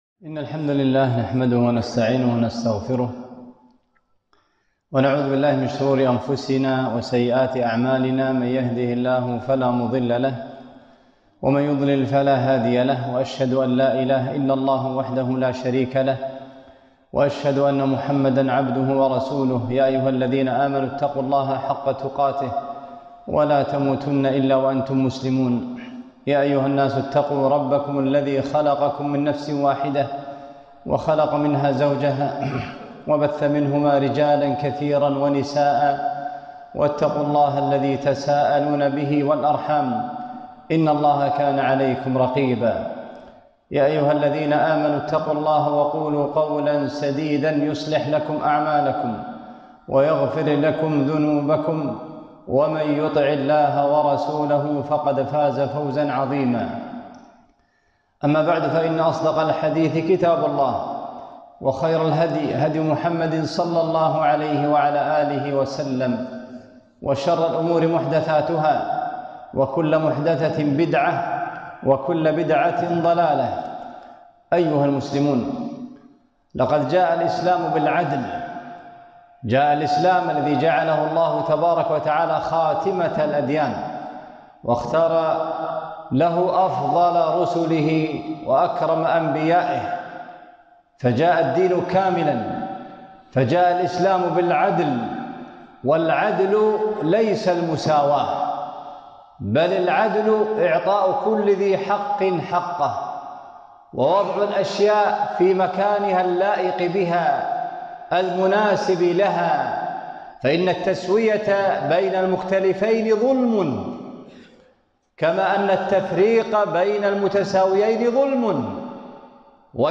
خطبة - المرأة وموقف الإسلام والفكر النسوي منها